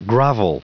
Prononciation du mot grovel en anglais (fichier audio)
Prononciation du mot : grovel